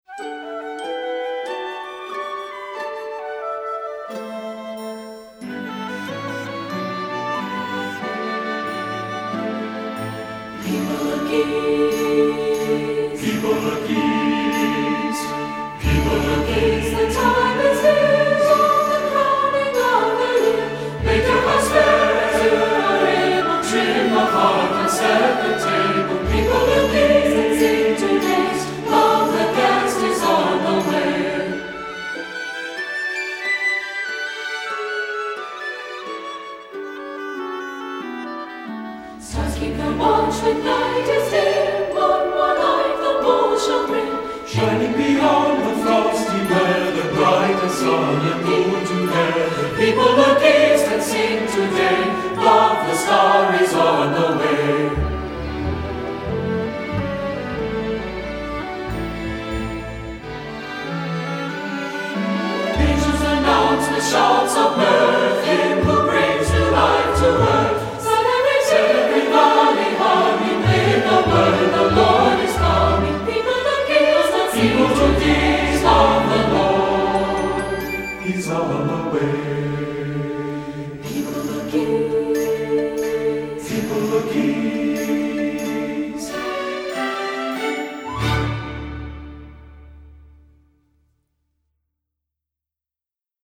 SAB Church Choir Music
Composer: Traditional French Carol